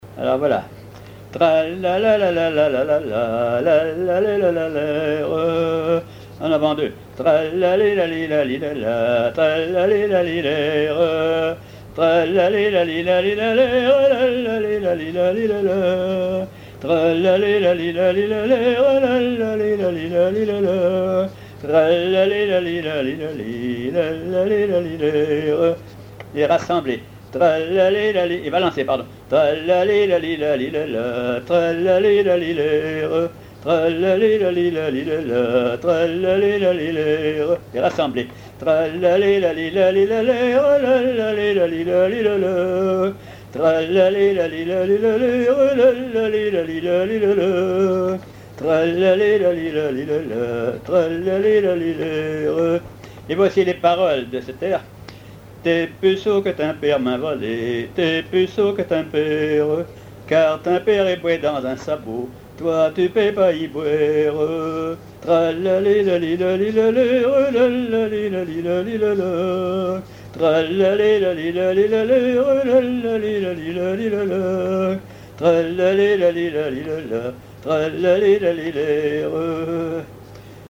Mémoires et Patrimoines vivants - RaddO est une base de données d'archives iconographiques et sonores.
Couplets à danser
chansons à ripouner ou à répondre
Pièce musicale inédite